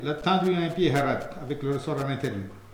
Langue Maraîchin
Patois - ambiance
Catégorie Locution